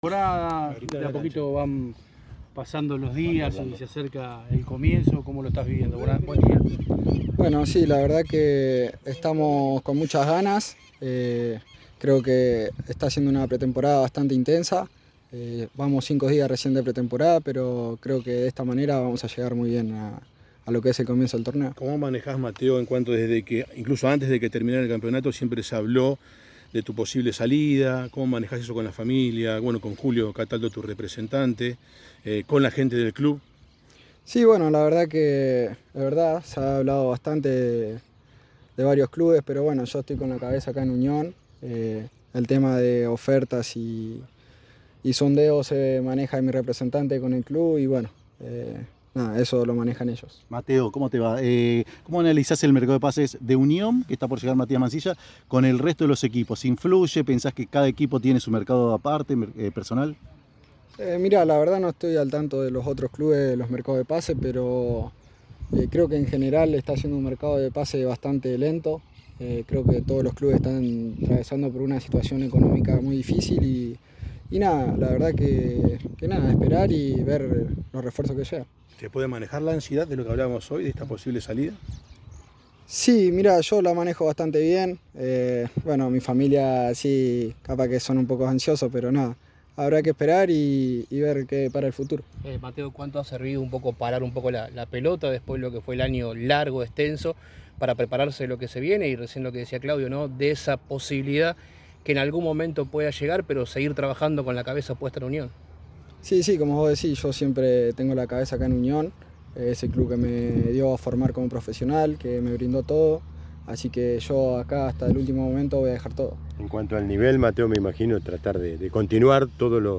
Unión atraviesa una exigente pretemporada de cara al inicio de la competencia oficial y, en ese contexto, Mateo Del Blanco tomó la palabra luego de un entrenamiento intenso.
“Está siendo una pretemporada bastante intensa. Vamos cinco días recién, pero creo que de esta manera vamos a llegar muy bien al comienzo del torneo”, señaló Del Blanco ante los micrófonos de EME, destacando el nivel de exigencia que propone el cuerpo técnico desde el arranque.
Escuchá la palabra de Mateo Del Blanco en EME: